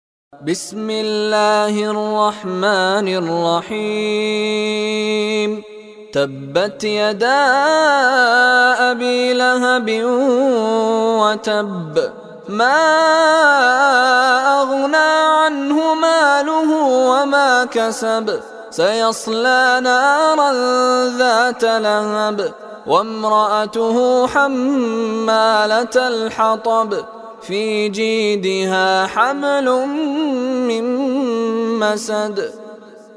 منتخب تلاوتهای شیخ مشاری العفاسی